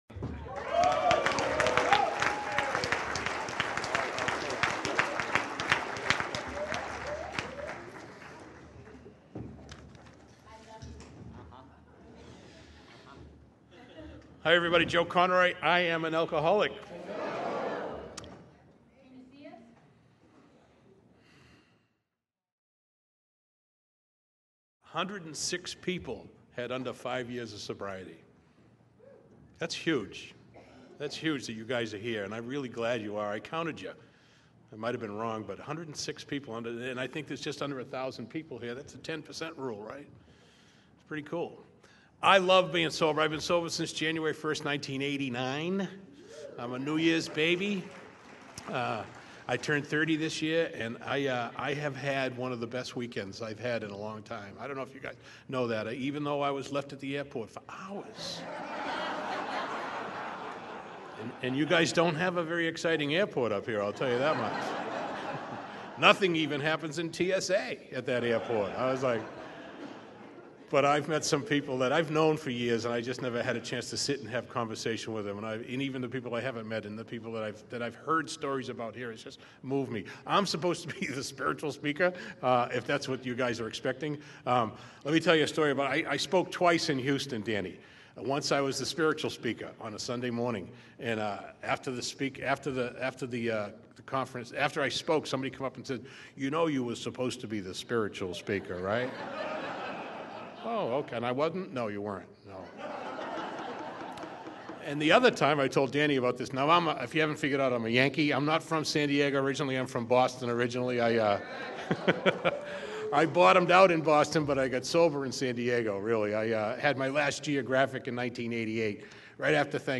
Rogue Round-Up Grants Pass OR 2019 – Popular AA Speakers